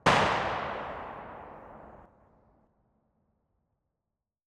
AR2_ShootTail 02.wav